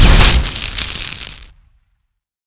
plasma_hit.wav